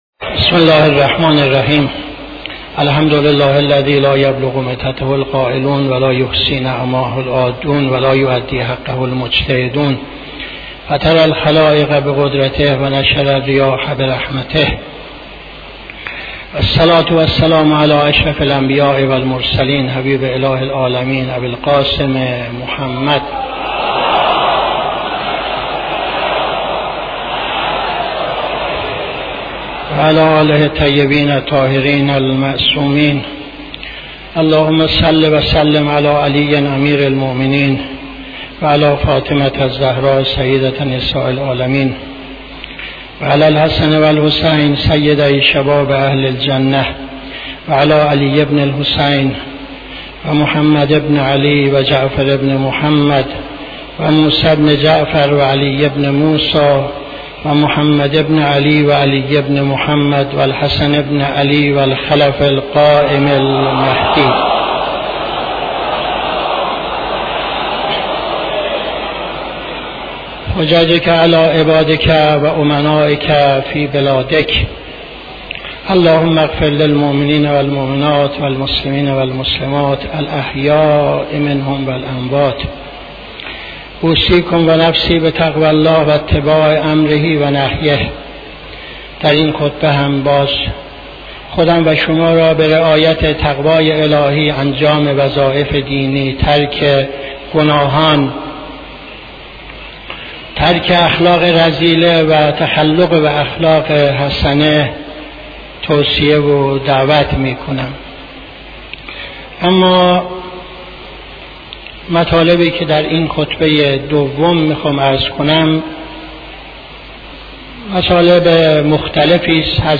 خطبه دوم نماز جمعه 10-07-77